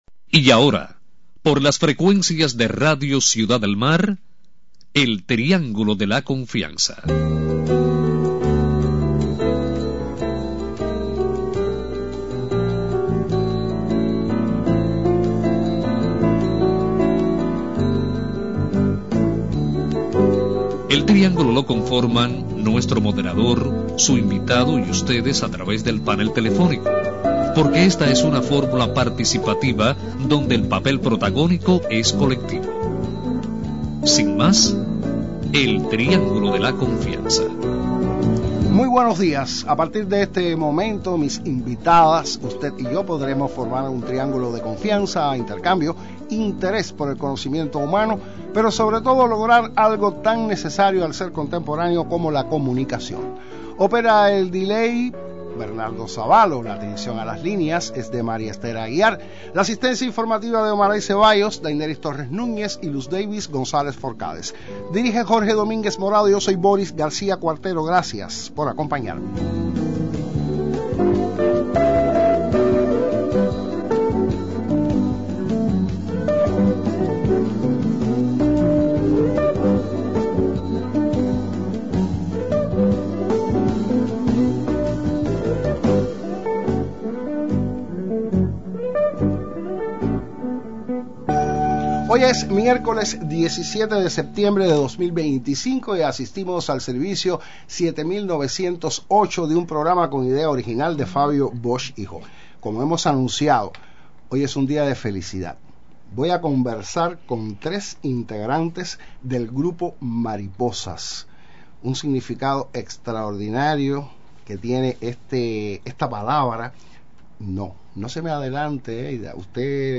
Integrantes del grupo provincial Mariposas de Cienfuegos comparten con los oyentes del Triángulo de la confianza.